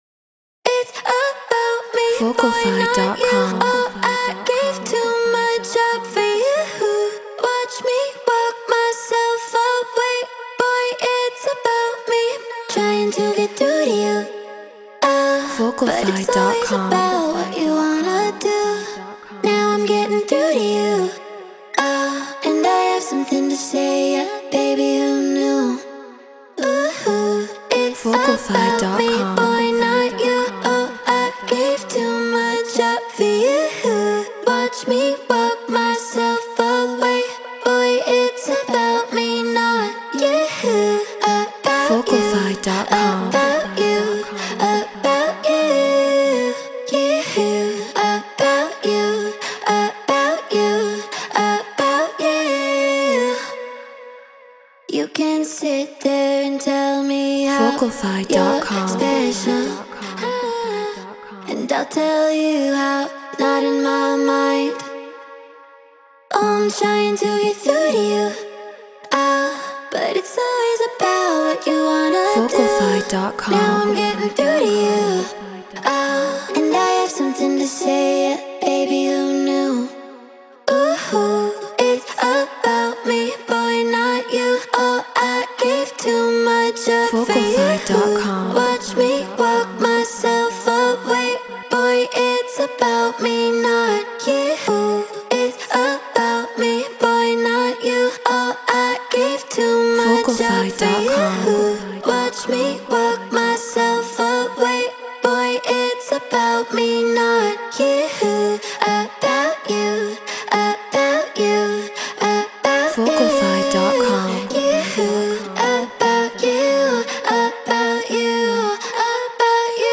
UKG 142 BPM G#min
Shure SM7B Scarlett 2i2 4th Gen Ableton Live Treated Room